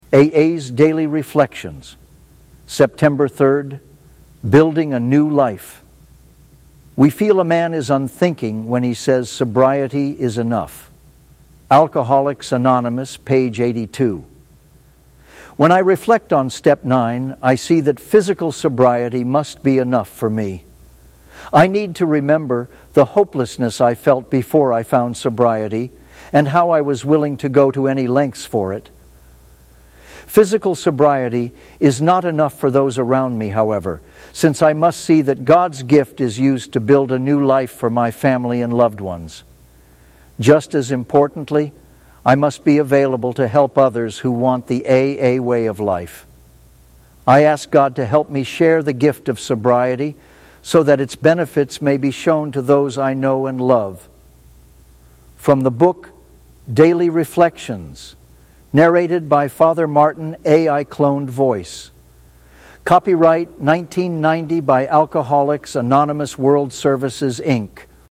Daily Reflections